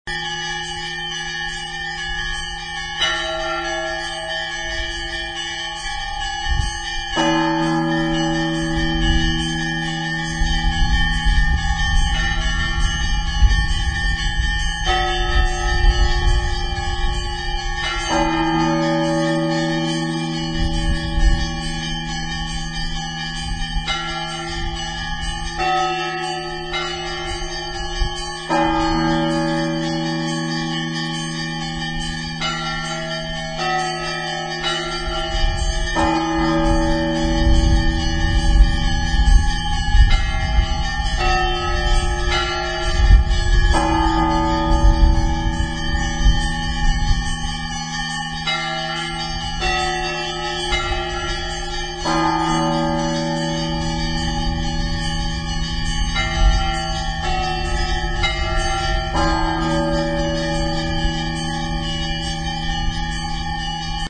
Orthodox Mass
The bells ring at 09.00, and those who listen know that it is the start.